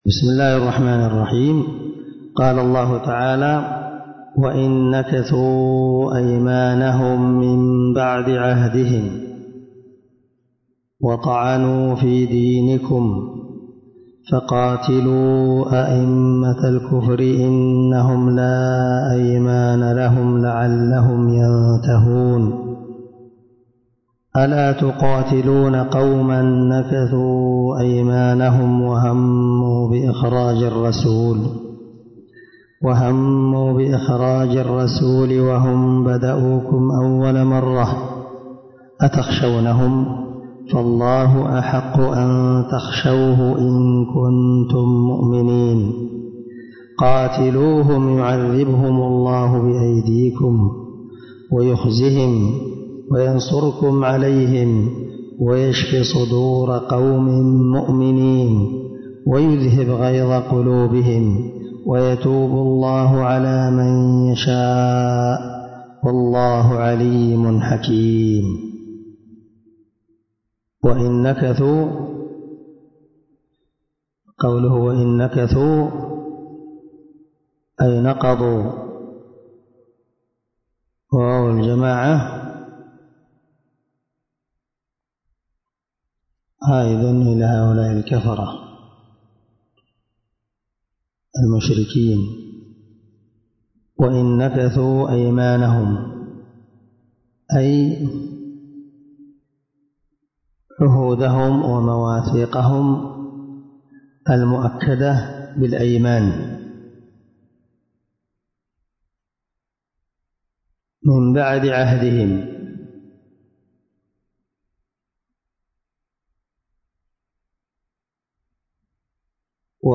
534الدرس6تفسير آية ( 12 – 15 ) من سورة التوبة من تفسير القران الكريم مع قراءة لتفسير السعدي